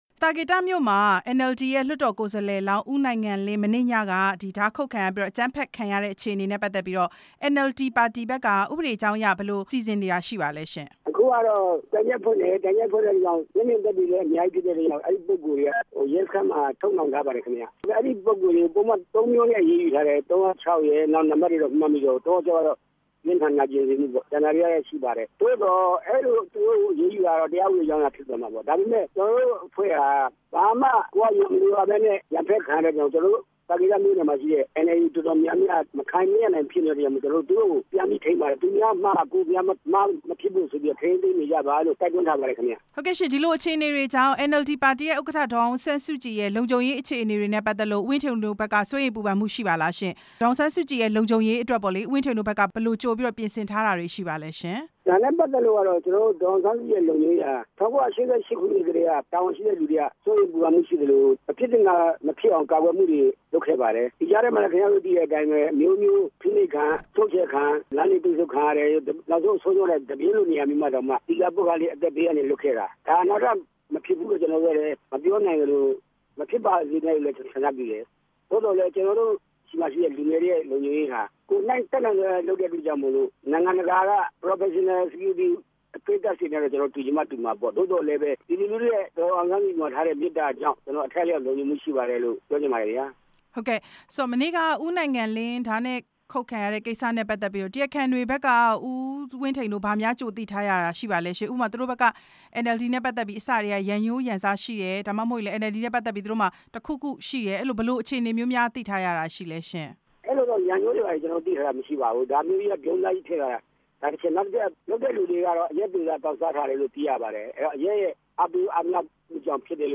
NLD ပါတီဝင် ၃ ဦး ဓားခုတ်ခံရတဲ့အကြောင်း ဦးဝင်းထိန်နဲ့ မေးမြန်းချက်